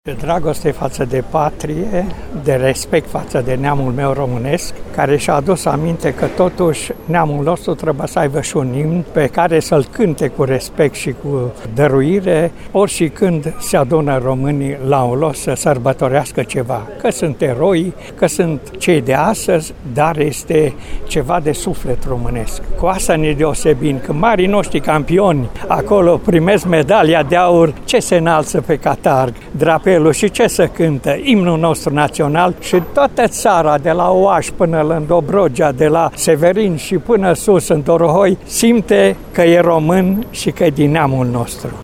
Militari, poliţişti, pompieri şi jandarmi au defilat, astăzi, la Timişoara, de Ziua Imnului Naţional.
Invitaţi au fost miltari în rezervă dar şi veterani de război, care au punctat importanţa acestei sărbători.